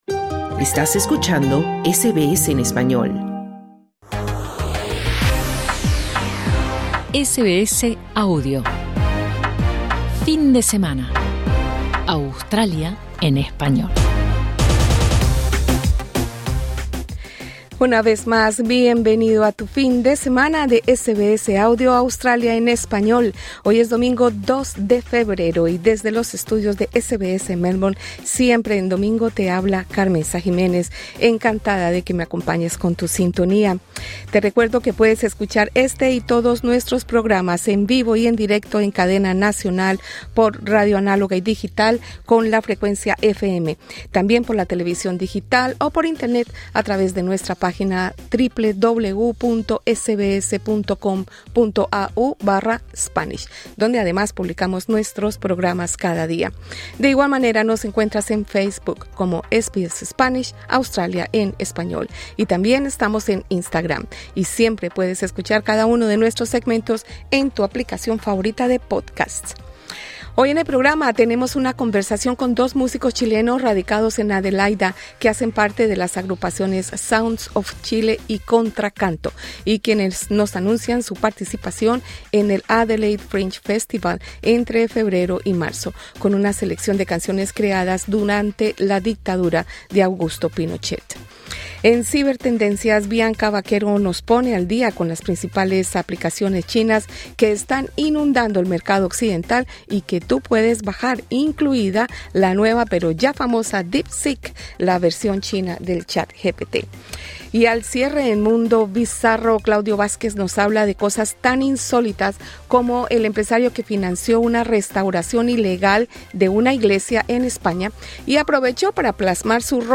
Deportes: A League / Champions League / Suramericano Sub-20 / Copa Davis / Cadel Evans Great Ocean Race / ¿Una estatua para Djokovic en Melbourne?